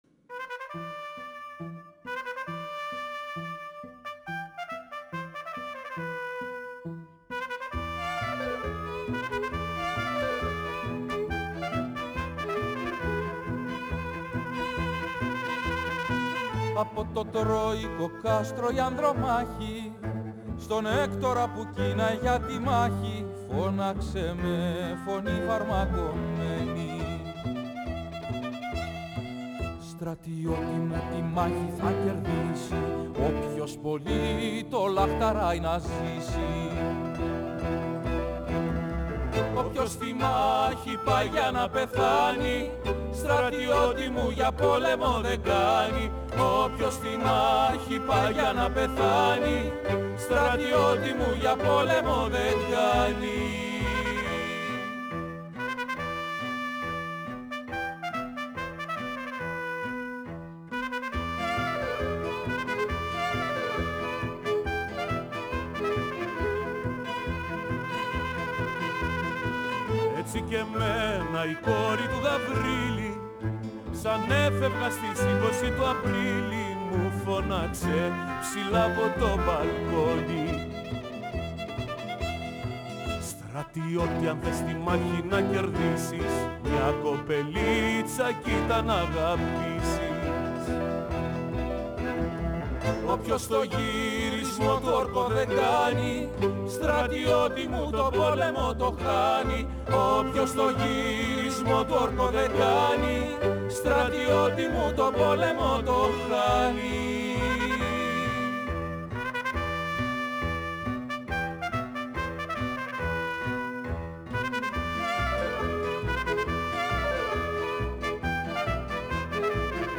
Η συνέντευξη πραγματοποιήθηκε την Τρίτη 17 Ιανουαρίου 2023